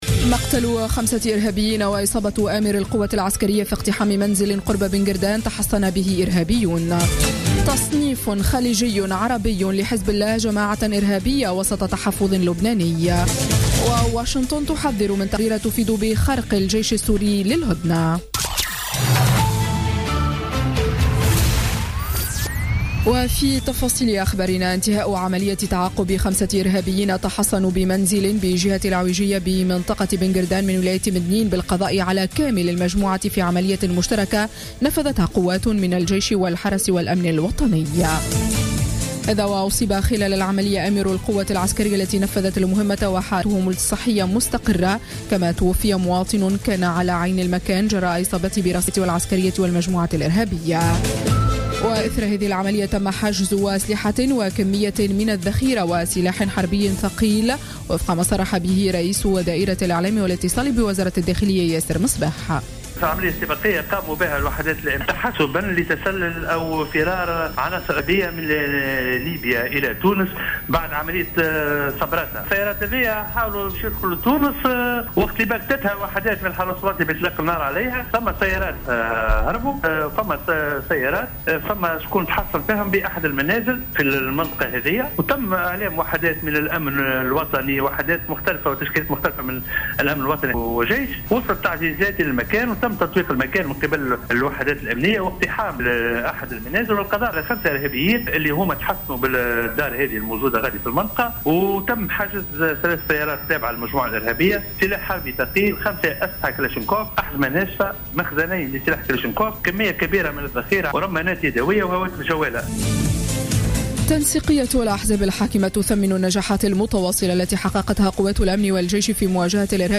نشرة أخبار السابعة صباحا ليوم الخميس 3 مارس 2016